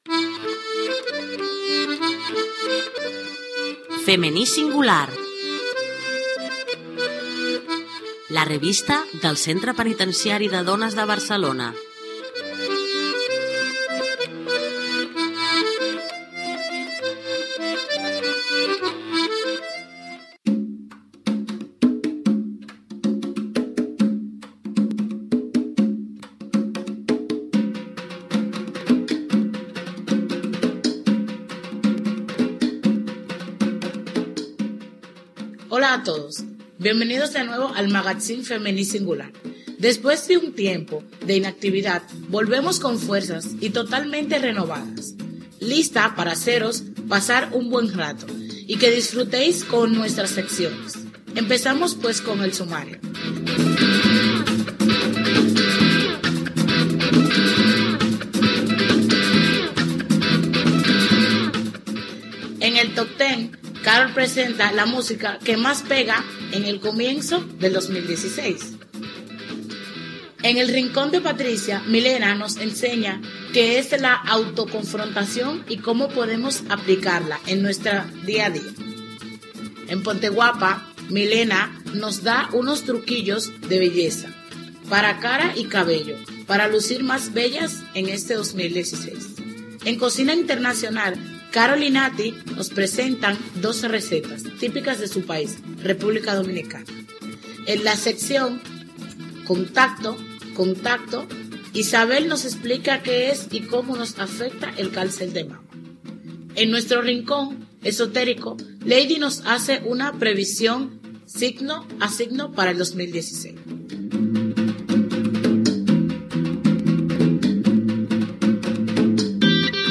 Careta del programa, presentació, sumari de continguts, "El top 10 de Wad-Ras".